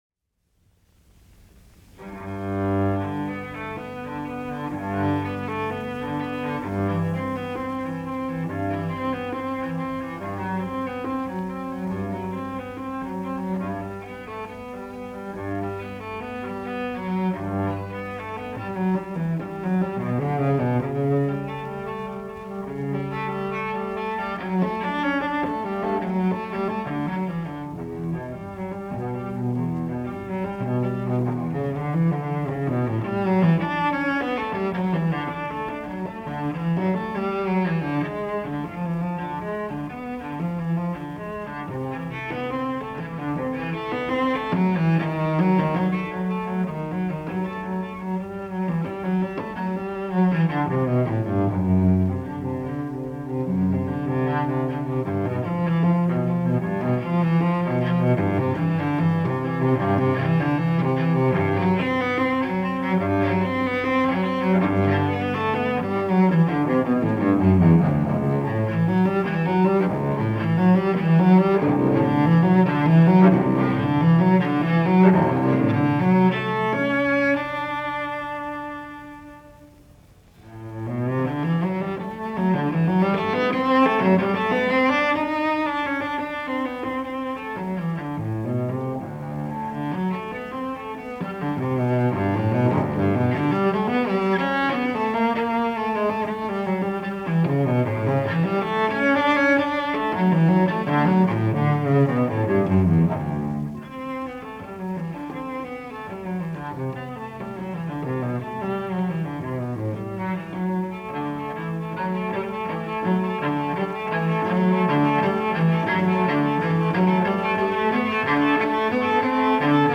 cello suite